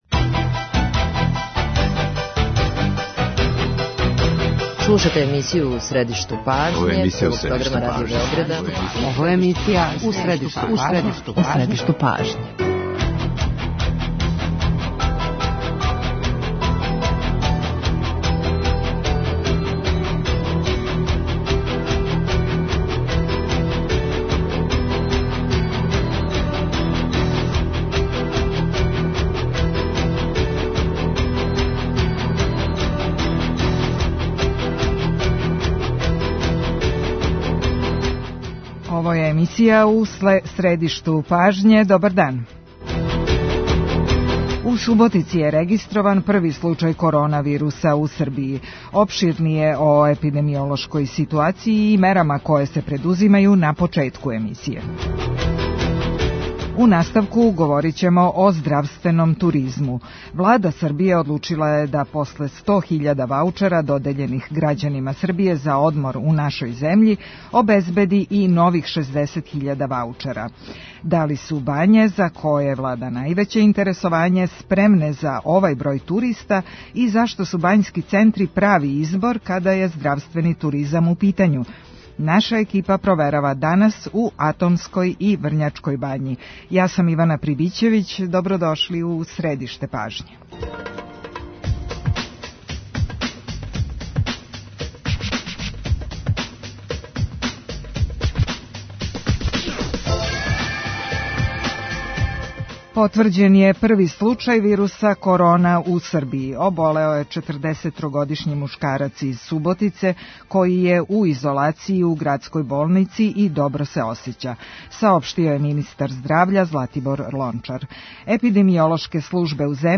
Здравствени туризам – уживо из Врњачке Бање
Емисија У средишту пажње, овога петка, 6. марта уживо ће бити емитована из Врњачке Бање, из хотела Меркур.